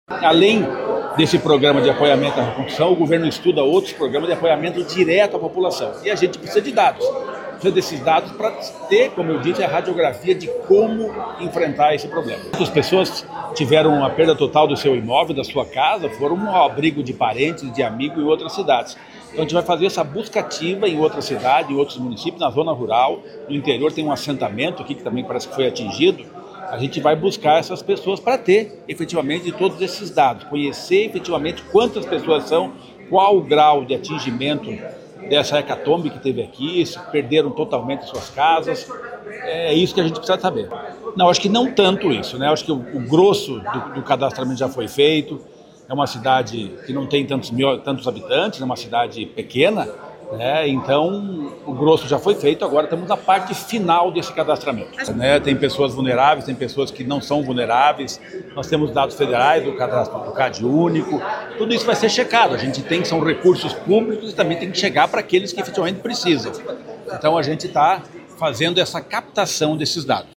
Sonora do secretário do do Desenvolvimento Social e Família, Rogério Carboni, sobre as iniciativas de reconstrução para a comunidade de Rio Bonito do Iguaçu